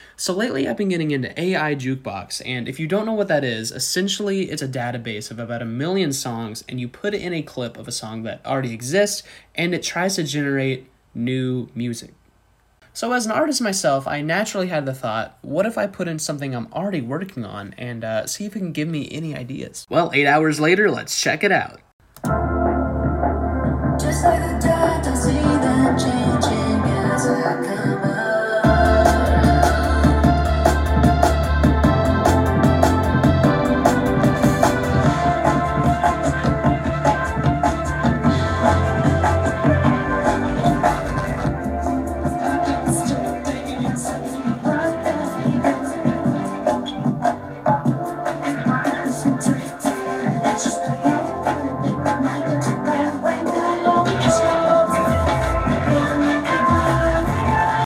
AI generated music... what do sound effects free download